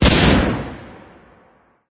explode.mp3